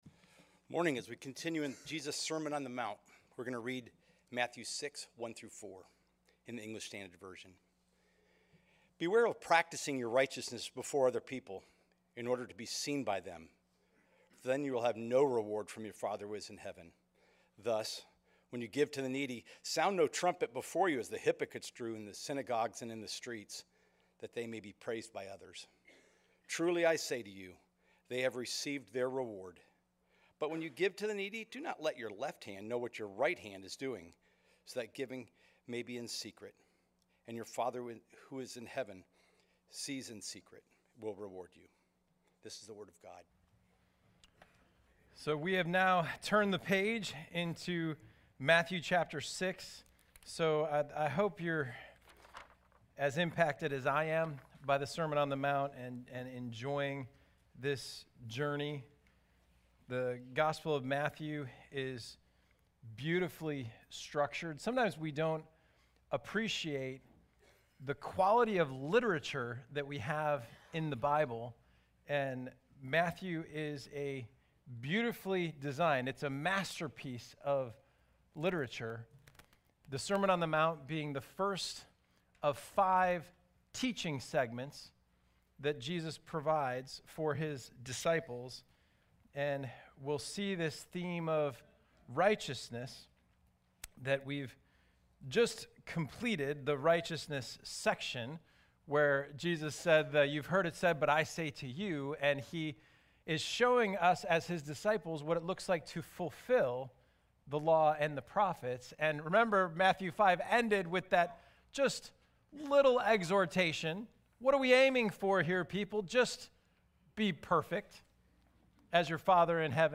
Read the message here: The Grace of Giving